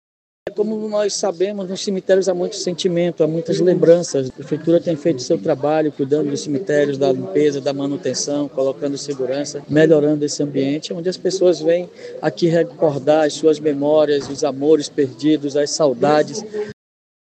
Neste domingo, durante visita aos cemitérios de Manaus, o prefeito de David Almeida, destacou que ações foram realizadas para atender o grande fluxo visitantes durante o dia dos finados.